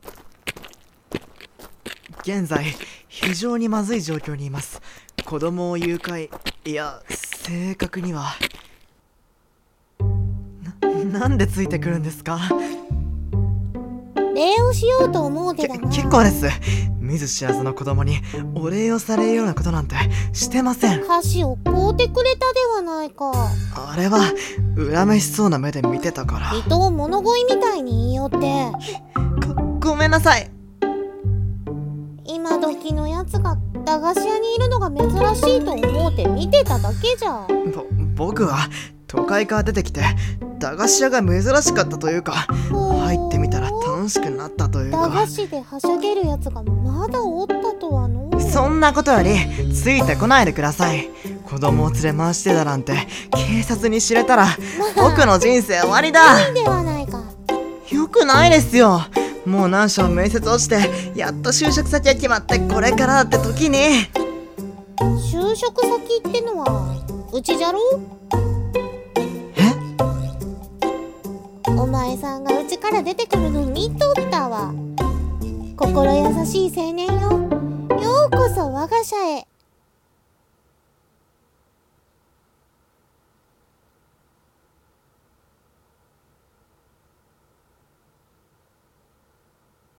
【声劇】ようこそ 我が社へ